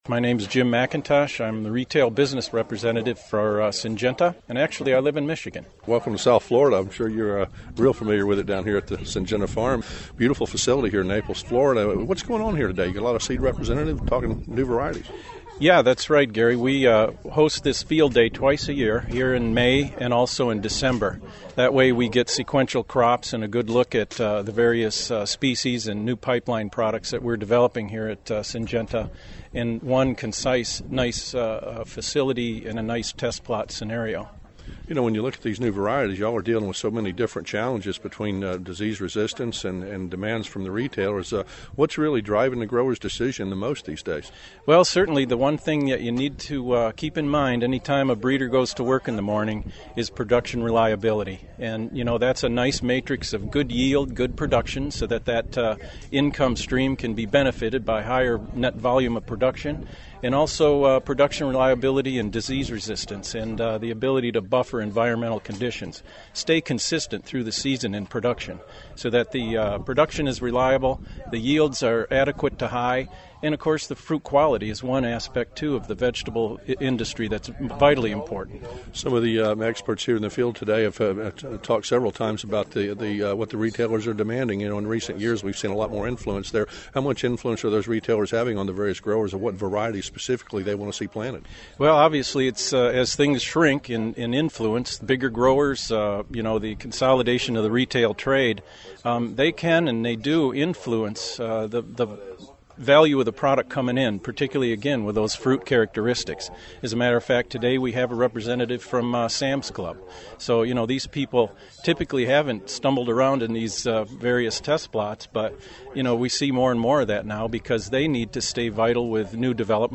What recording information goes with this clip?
Southeastern growers, in this news post you can hear what’s being said in this crowd that will affect what’s grown this year on the farms in this deep southeast region.